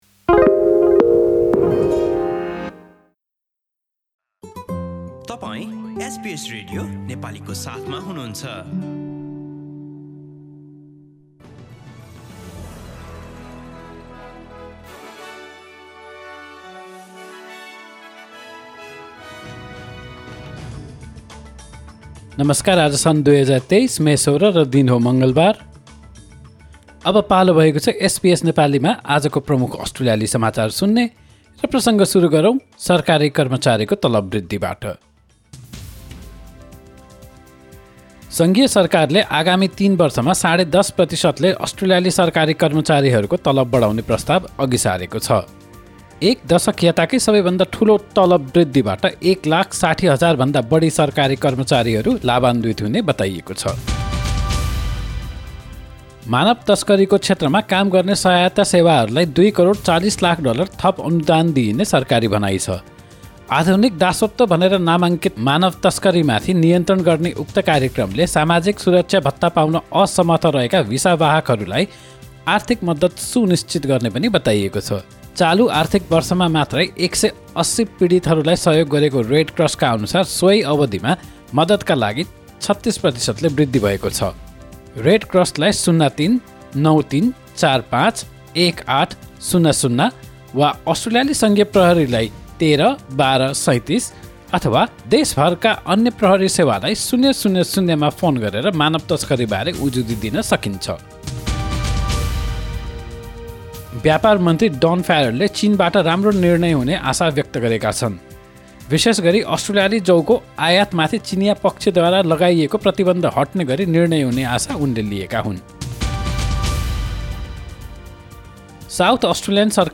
एसबीएस नेपाली प्रमुख अस्ट्रेलियाली समाचार : मङ्गलवार, १६ मे २०२३